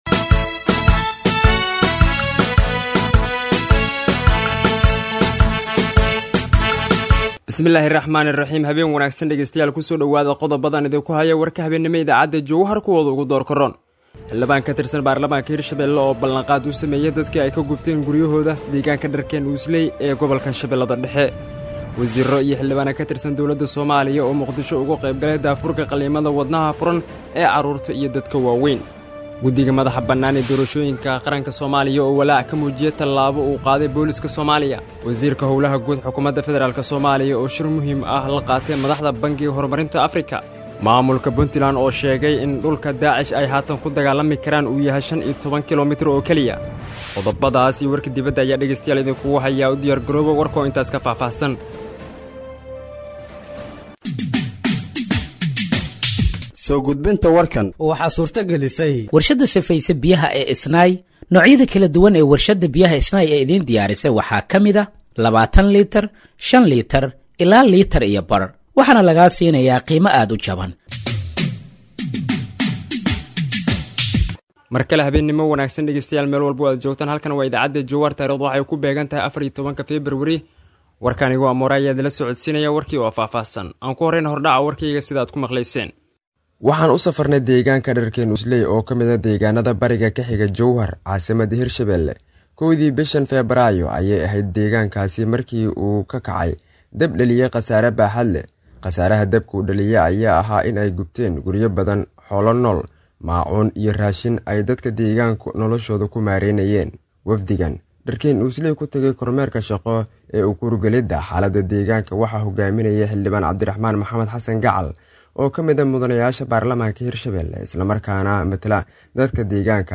Dhageeyso Warka Habeenimo ee Radiojowhar 14/02/2025